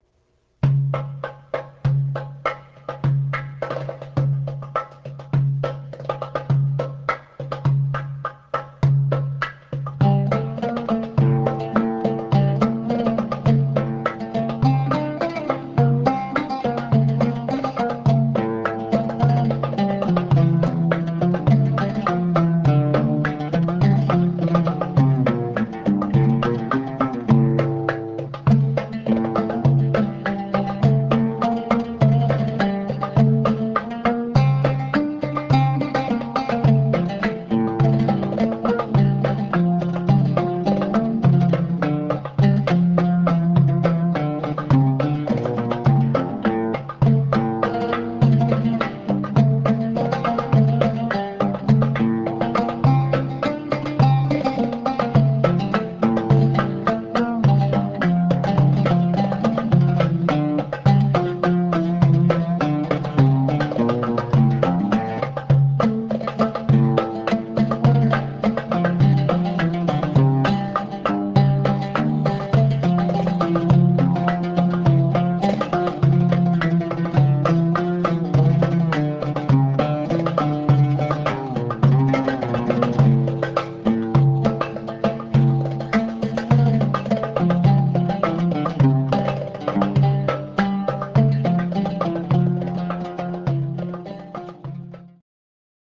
oud (Middle Eastern lute) and nay (cane flute)
"Walking" Maqsoum